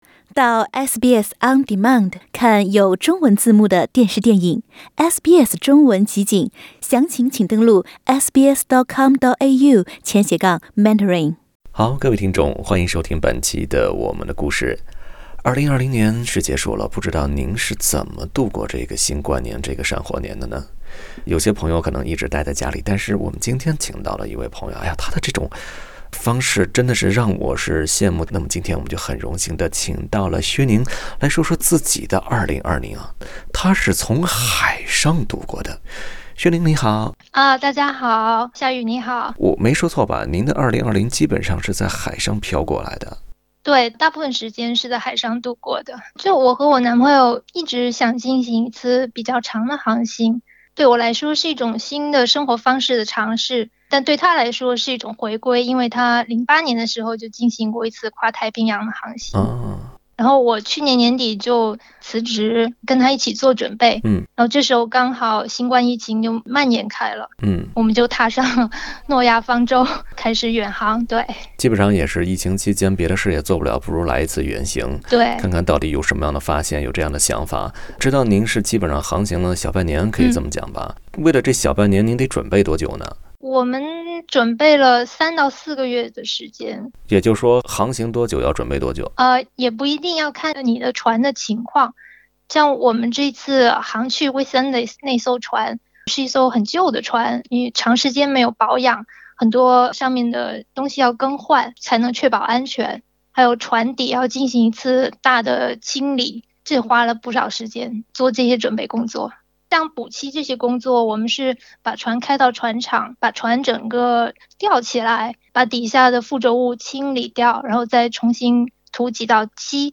（点击图片，收听采访）